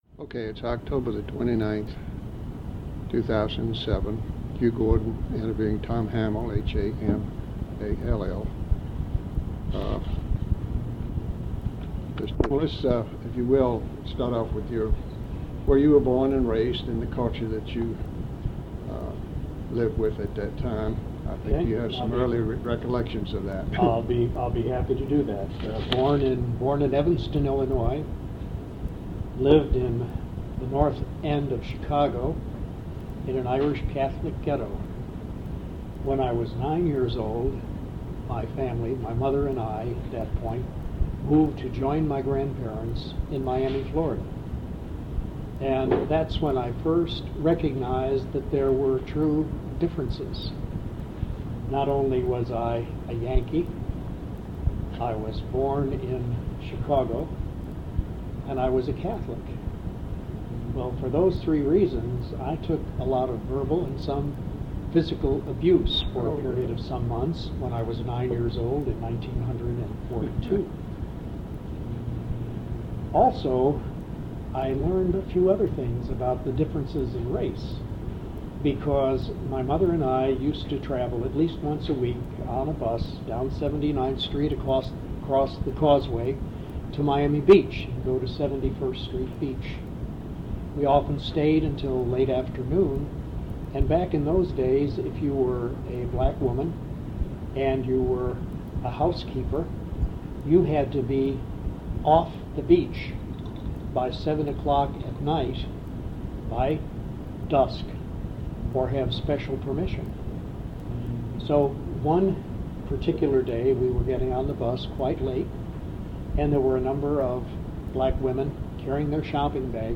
Interview
Sound recording digitized from an audiocassette